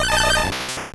Cri de Triopikeur dans Pokémon Or et Argent.